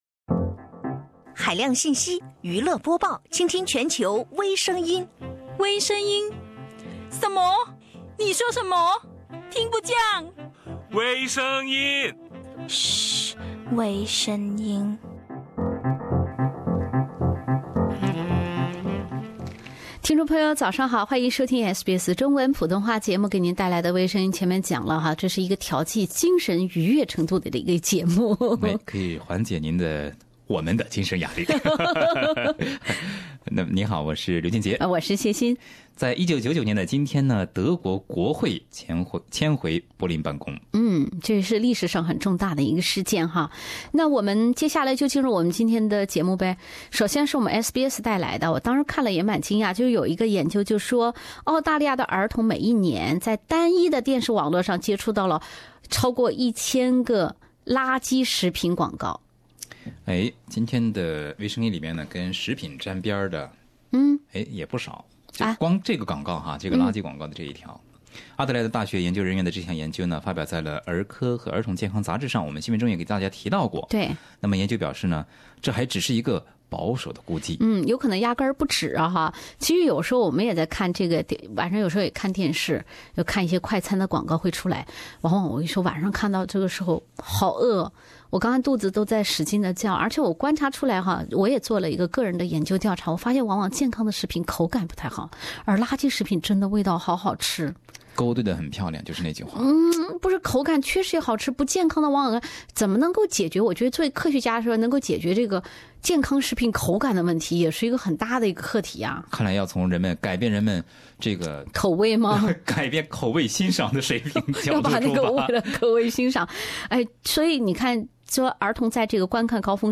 另类轻松的播报方式，深入浅出的辛辣点评，包罗万象的最新资讯，点击收听本期《微声音》。